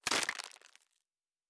Plastic Foley 08.wav